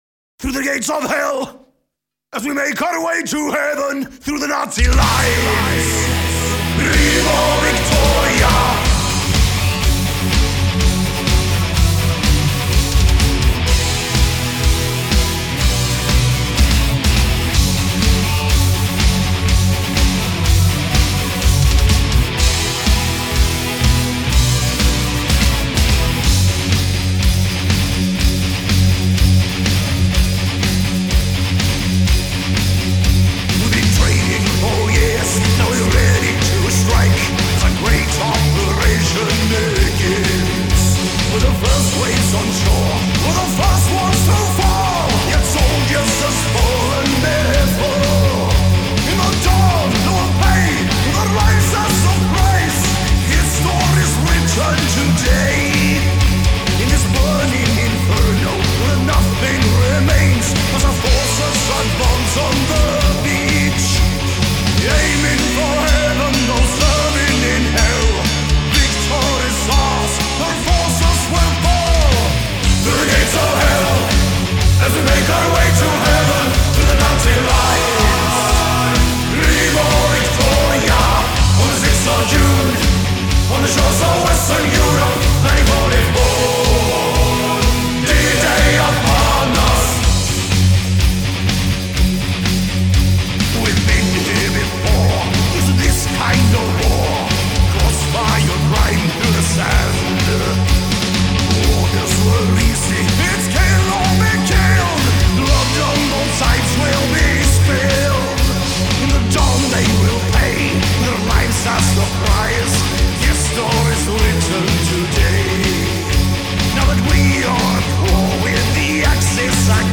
Жанры: Силовой металл